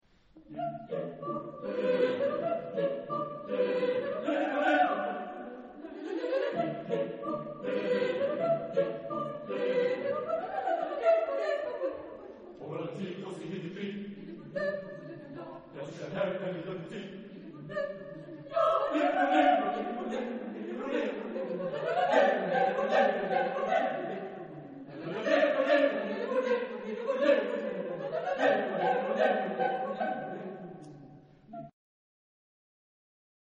Chorgattung: SATB  (4 gemischter Chor Stimmen )
Tonart(en): D-Dur
Aufnahme Bestellnummer: Internationaler Kammerchor Wettbewerb Marktoberdorf